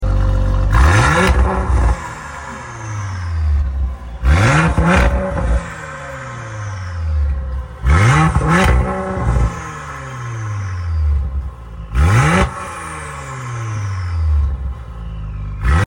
What yall think? Rev limiter sound effects free download
Rev limiter Mp3 Sound Effect What yall think? Rev limiter is at 4500 rpm’s. What Yall Think?